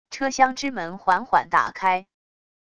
车厢之门缓缓打开wav音频